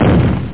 explode.mp3